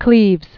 (klēvz)